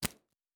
Fantasy Interface Sounds
Cards Place 05.wav